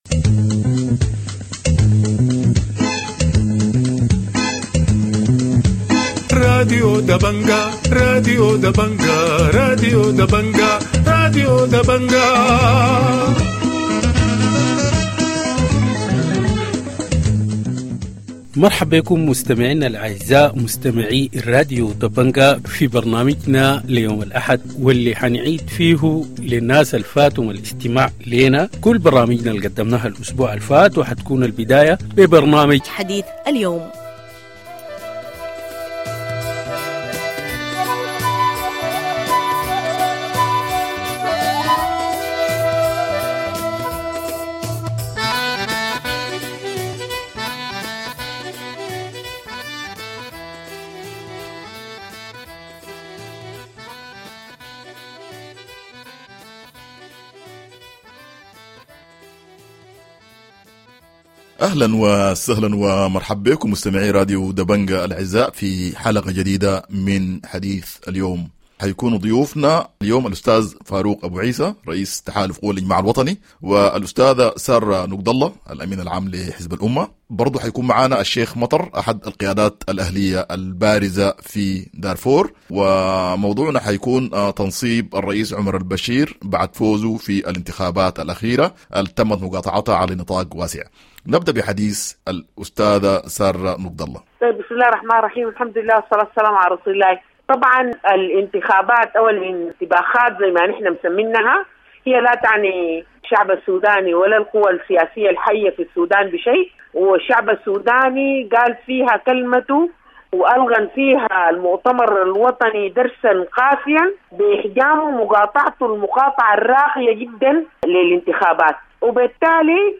Weekend news review